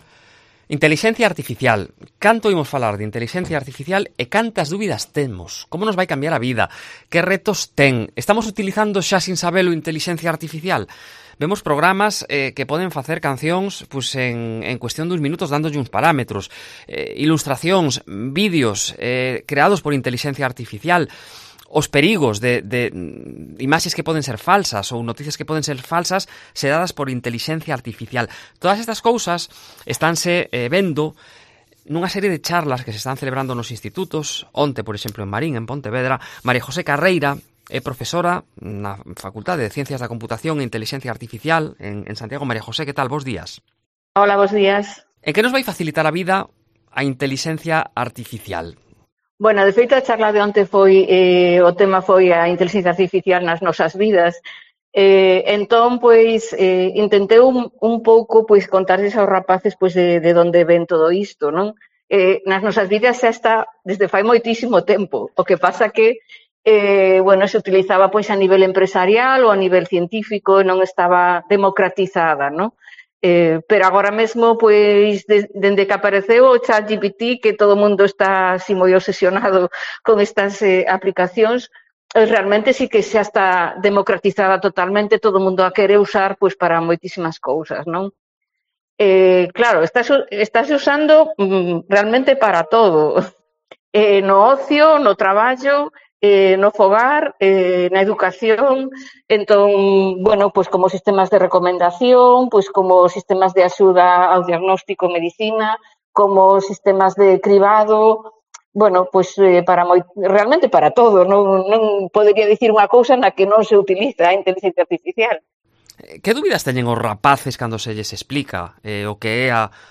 "La inteligencia artificial se usa desde hace mucho tiempo, lo novedoso es que con ChatGPT se ha democratizado", remarca en una entrevista en Herrera en Cope Galicia.